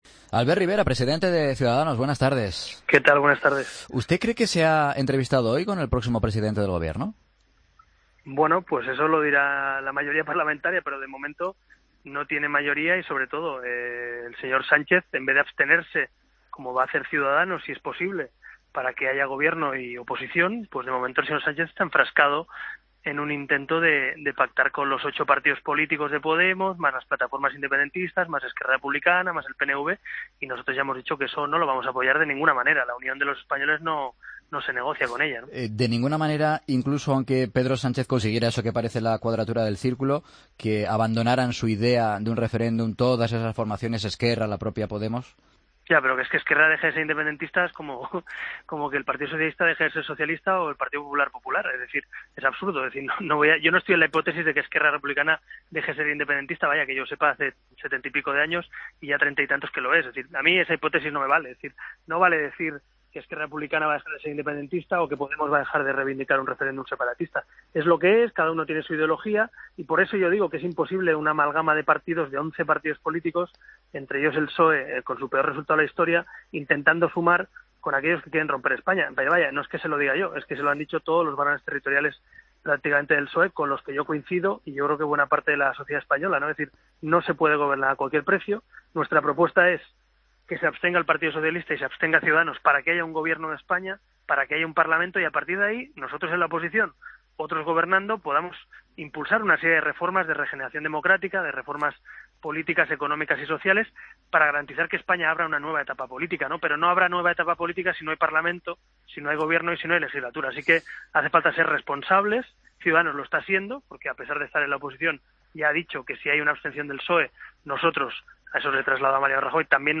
Escucha la entrevista a Albert Rivera en 'Mediodía COPE'